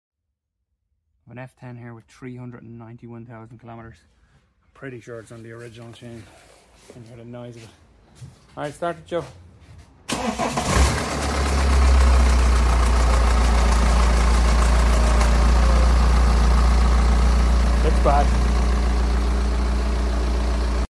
391 km on f10 timing chain noise